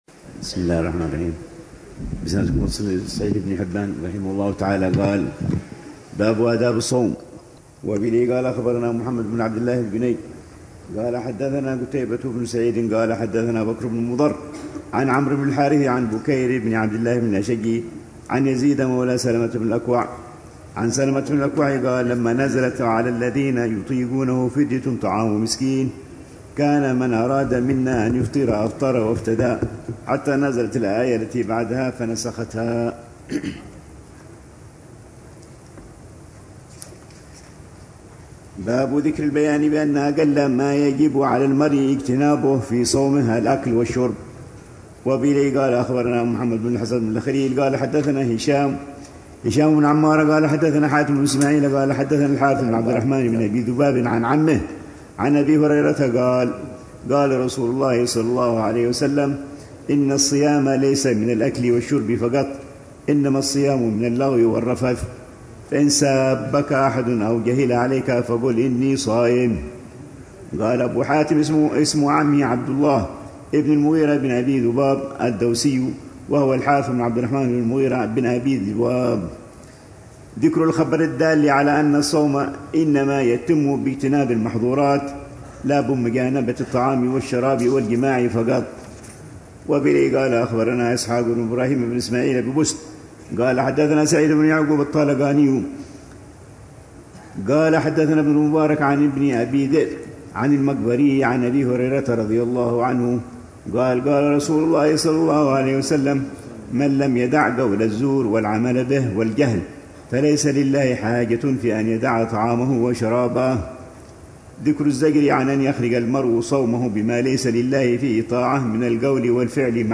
الروحة الرمضانية الرابعة عشرة بدار المصطفى لعام 1446هـ ، وتتضمن شرح الحبيب العلامة عمر بن محمد بن حفيظ لكتاب الصيام من صحيح ابن حبان، وكتاب ال